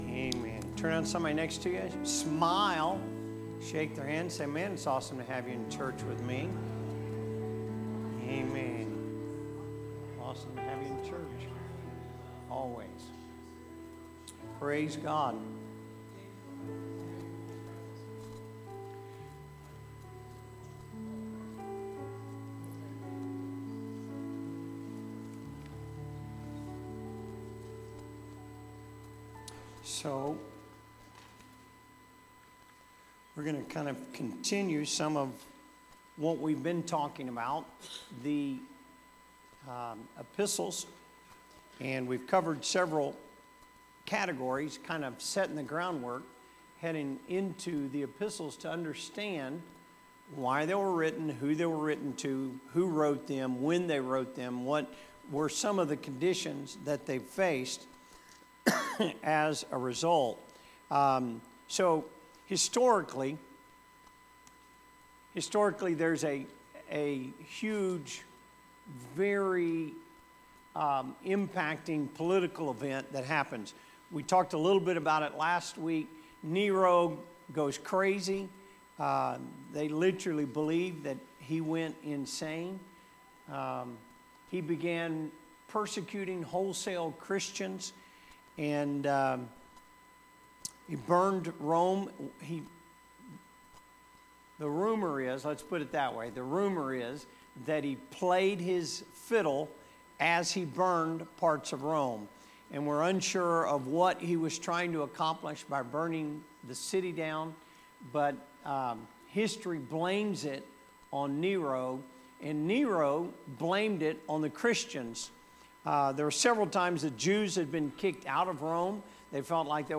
Sermons | Elkhart Life Church
Wednesday Service Epistles